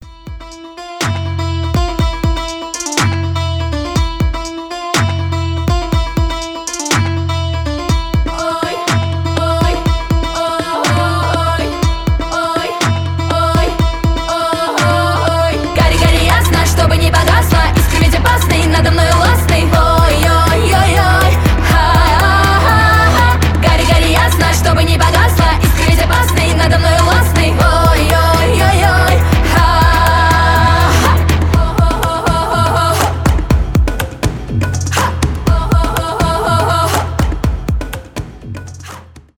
2020 » Русские » Поп Скачать припев